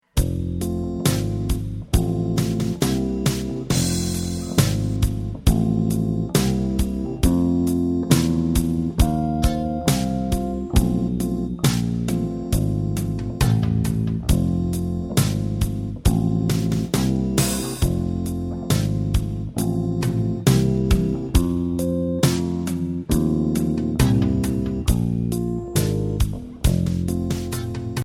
Voicing: Electric Bass